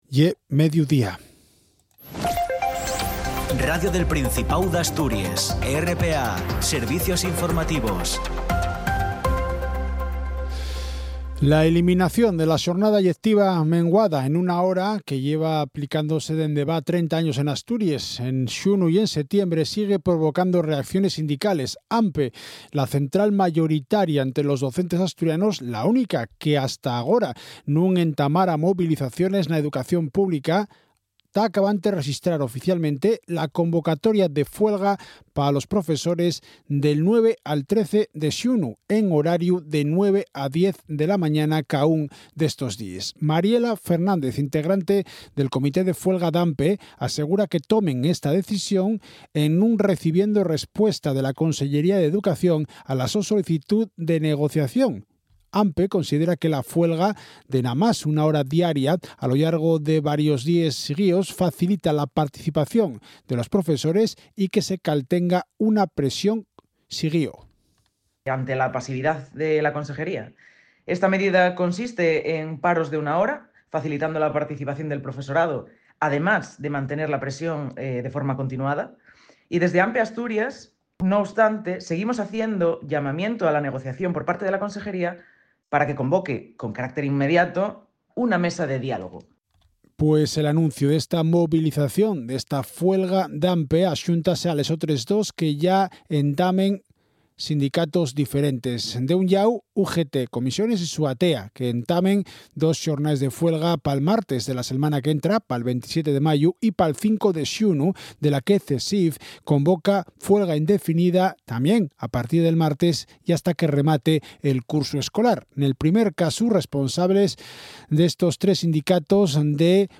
El boletín de las 12:00 horas tiene una duración de 10 minutos y se emite en asturiano. La actualidad general del día en nuestra lengua.